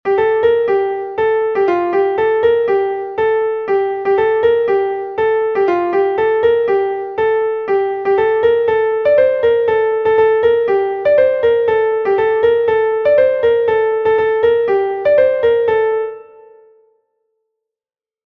An hini Goz est un Gavotte de Bretagne